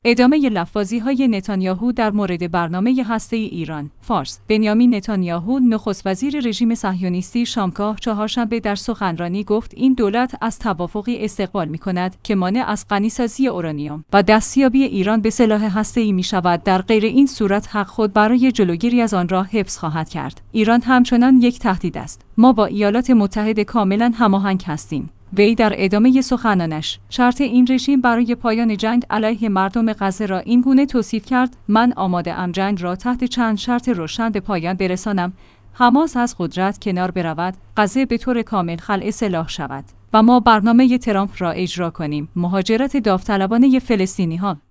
فارس«بنیامین نتانیاهو» نخست وزیر رژیم صهیونیستی شامگاه چهارشنبه در سخنرانی گفت این دولت از توافقی استقبال می‌کند که مانع از غنی‌سازی اورانیوم و دستیابی ایران به سلاح هسته‌ای می‌شود در غیر این صورت حق خود برای جلوگیری از آن را حفظ خواهد کرد.